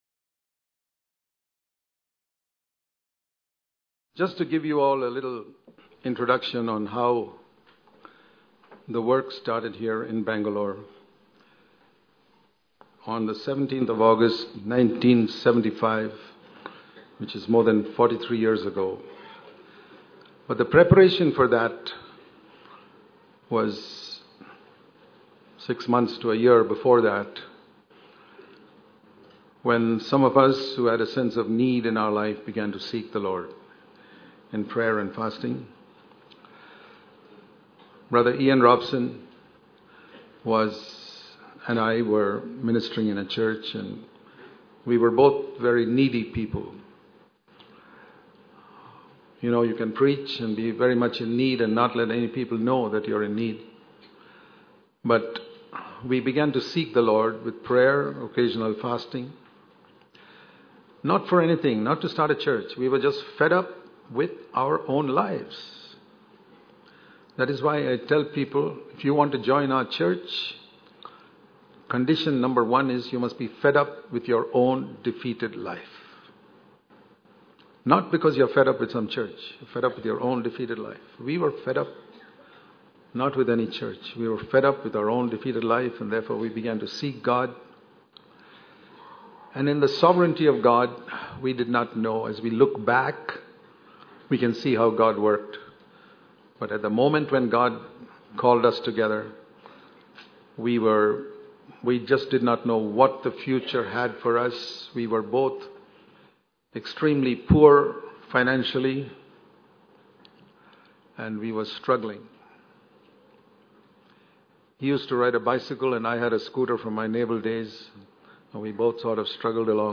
Bangalore Conference - 2018
Sermons